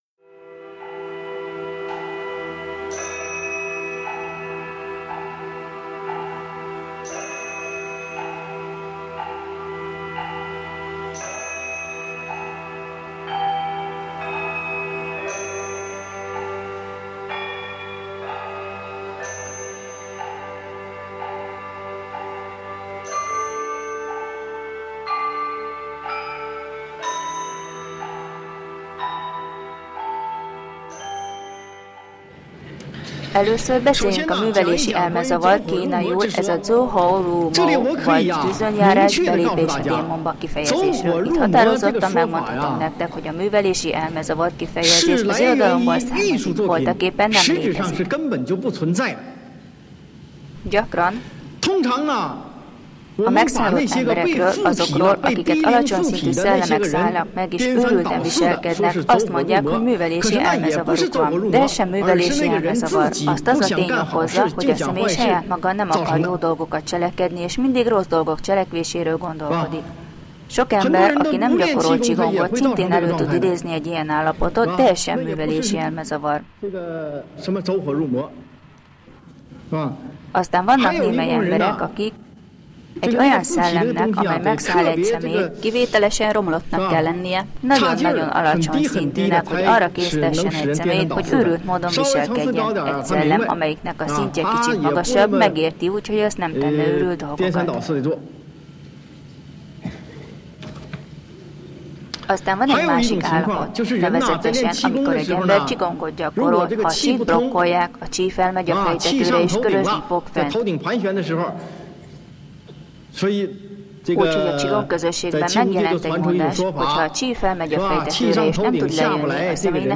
1. Előadás